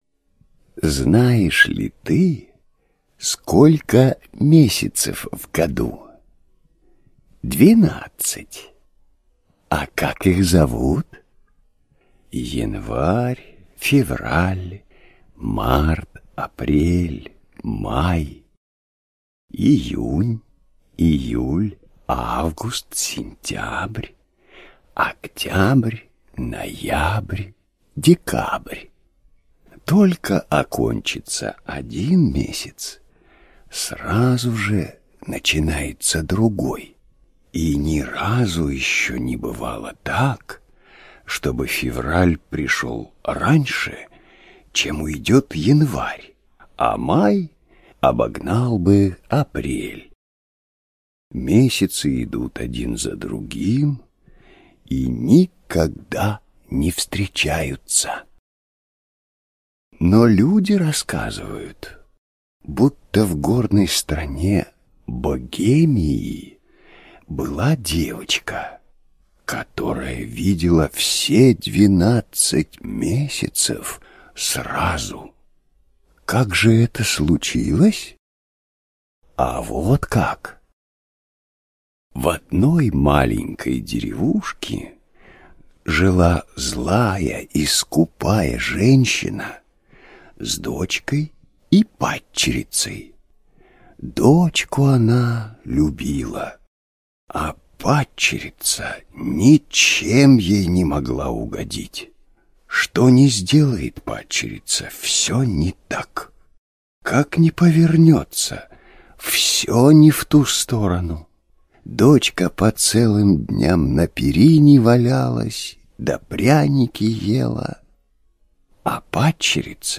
Двенадцать месяцев - аудиосказка Маршака - слушать онлайн